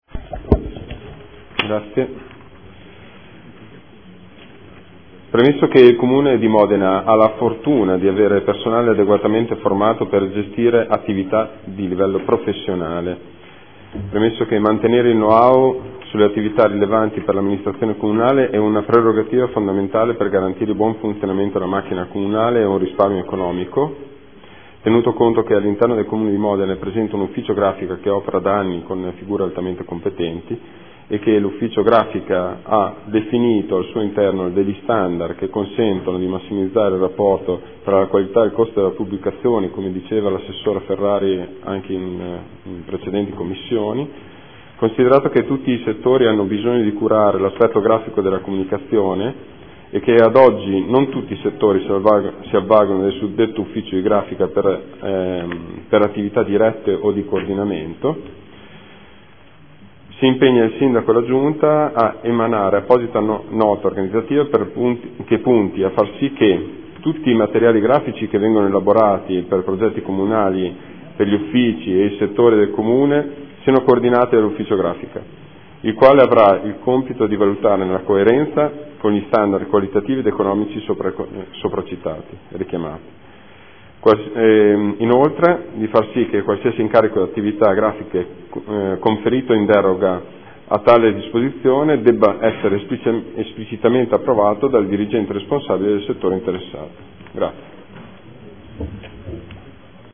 Seduta del 05/03/2015 Ordine del Giorno n.28658 presentato dal gruppo consiliare Movimento 5 Stelle